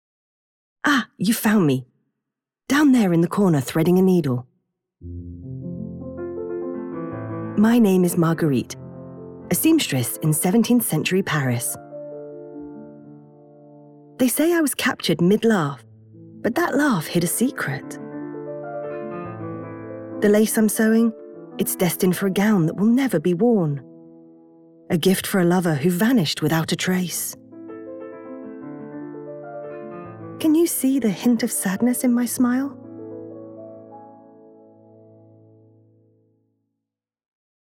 Englisch (Britisch)
Kommerziell, Junge, Natürlich, Freundlich, Corporate
Audioguide